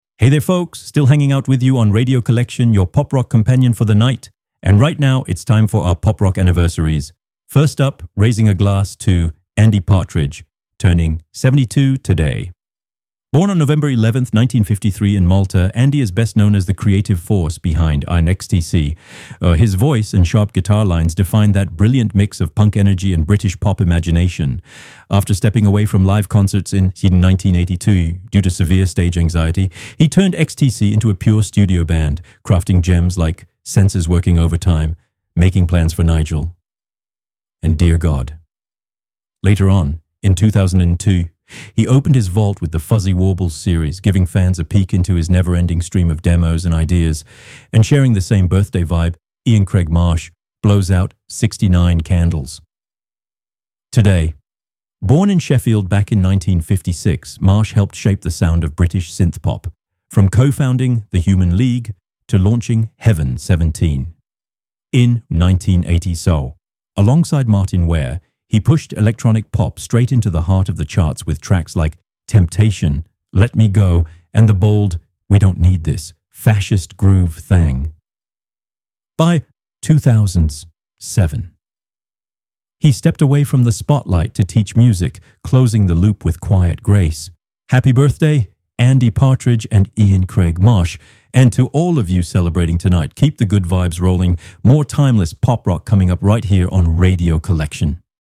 A lively column that mixes memories, anecdotes, and discoveries for a true daily journey through the history of Pop Rock fans' favorite artists, from the 70s to today.